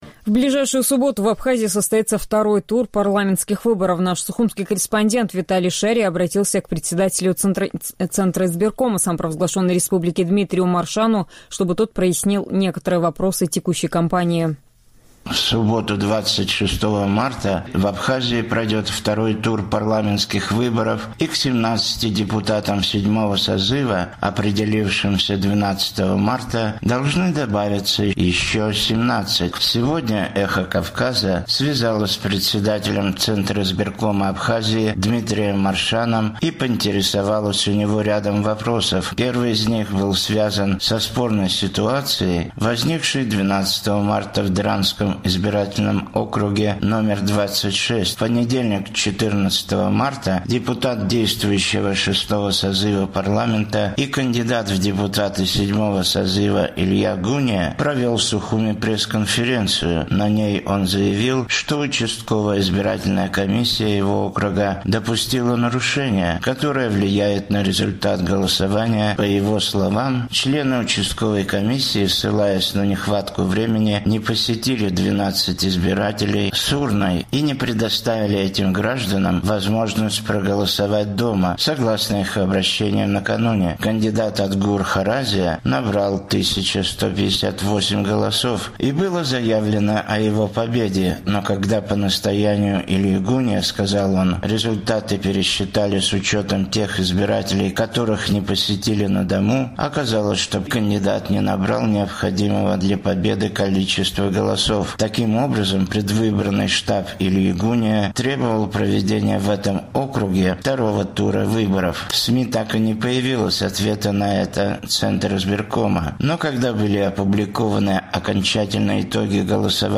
Сегодня «Эхо Кавказа» связалось с председателем Центризбиркома Абхазии Дмитрием Маршаном и поинтересовалось у него рядом вопросов. Первый из них был связан со спорной ситуацией, возникшей 12 марта в Драндском избирательном округе №26.